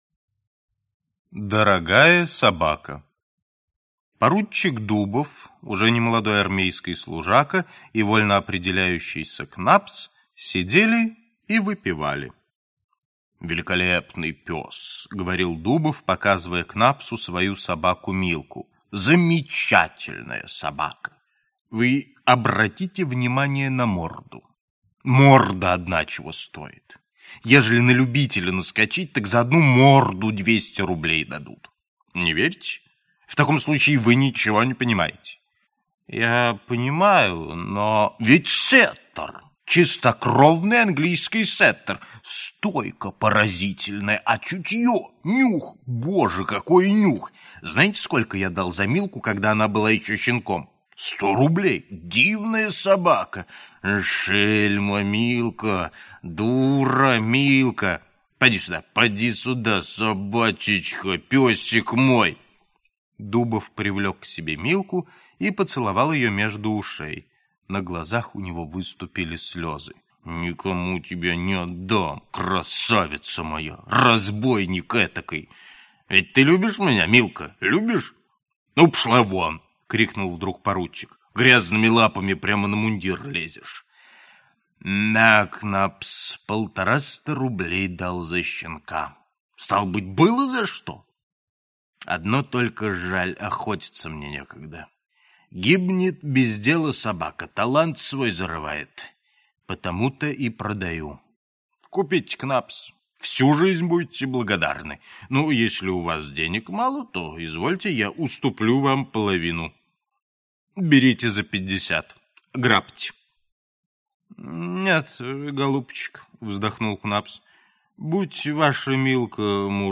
Аудиокнига Юмористические рассказы Антоши Чехонте | Библиотека аудиокниг